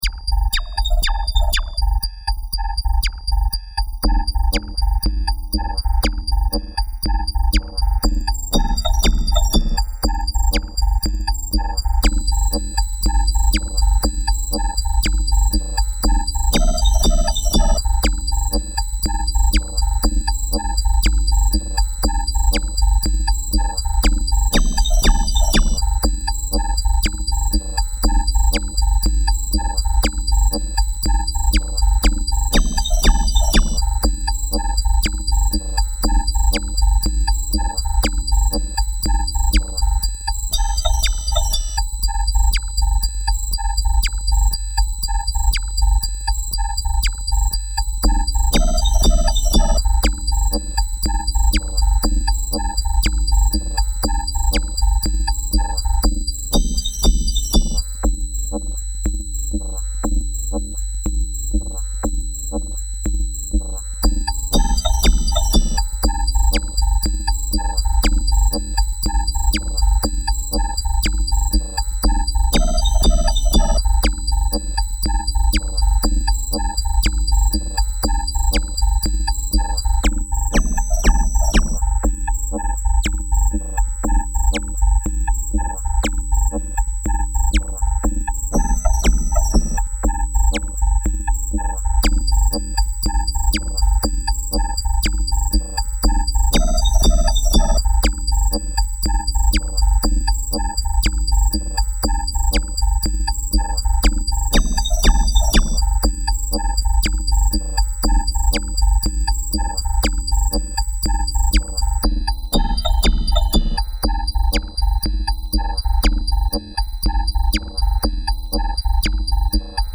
Música electrónica
melodía
sintetizador